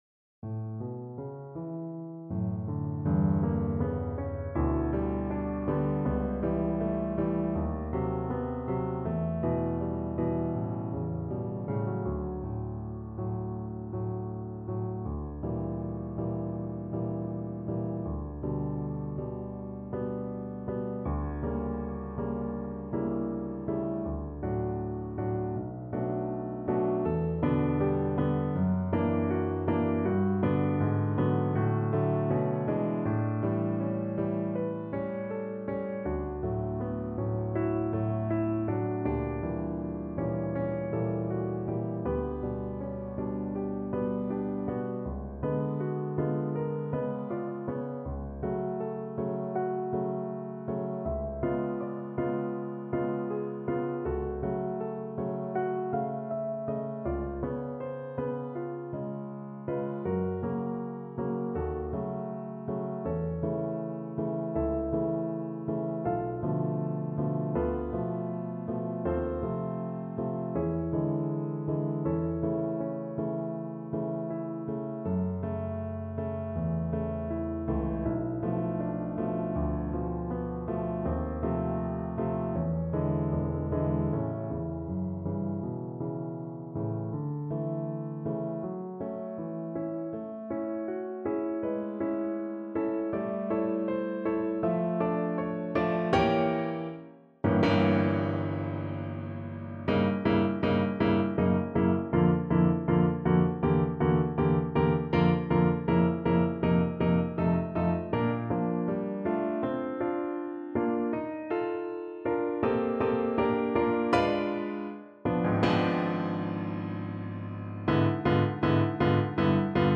Andante espressivo
Classical (View more Classical Viola Music)